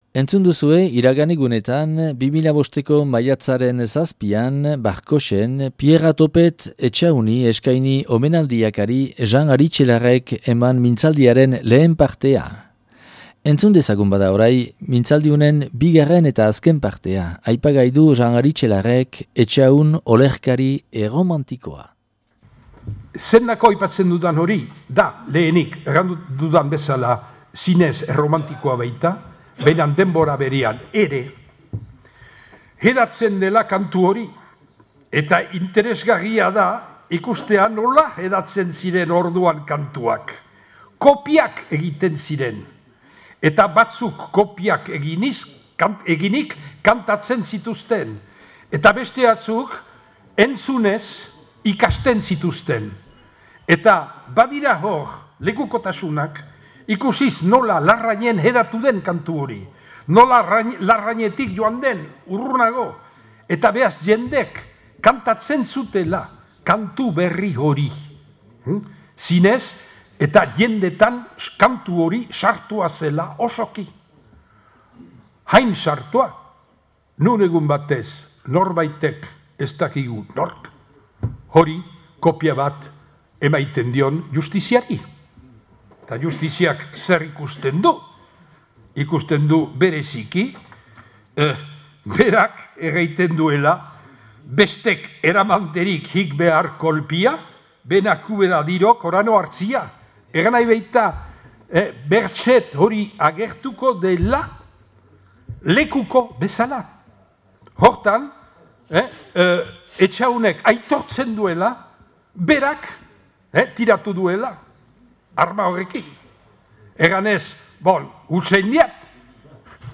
Pierra Topet Etxahun omenaldia - Barkotxen 2005. Maiatzaren 7an